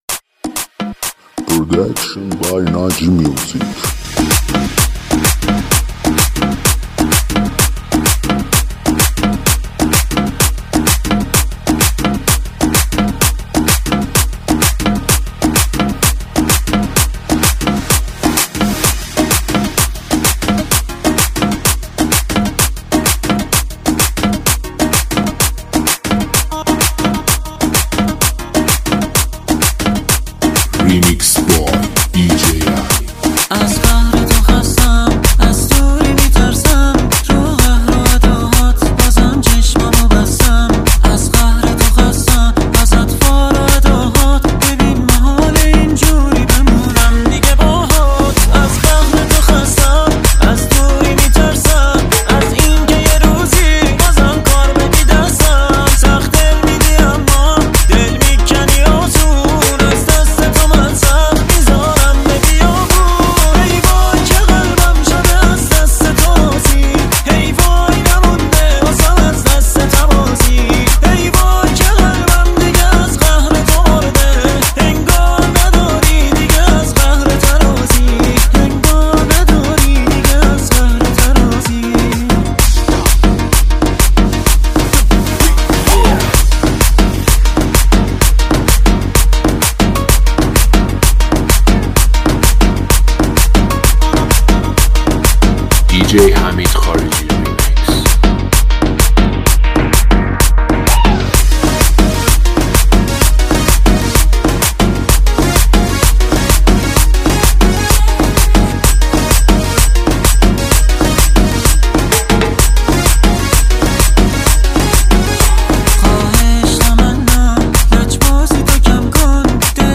ریمیکس شاد رقصی